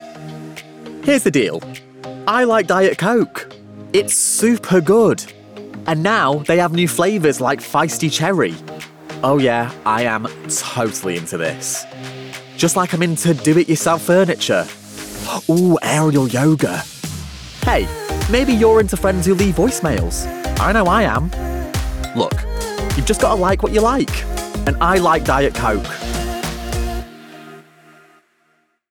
Voice Reel
Diet Coke - Conversational, Confident